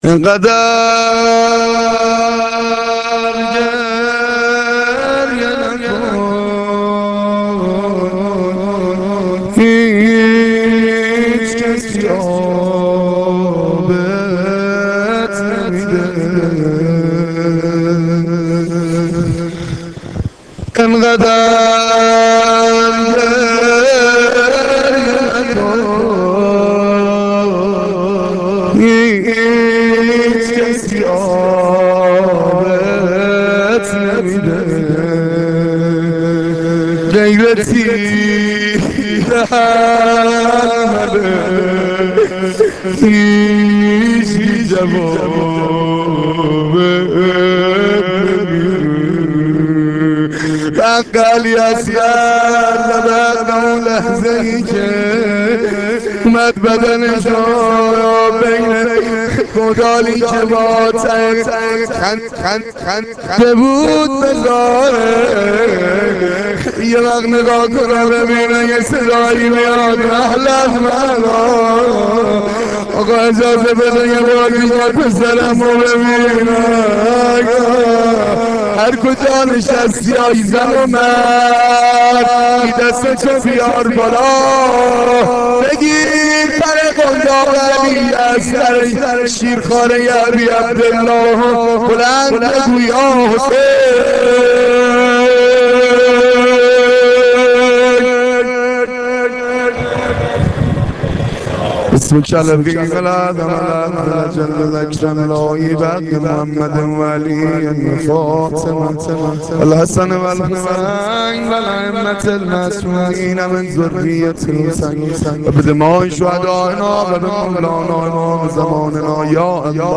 مداحی روضه شب هفتم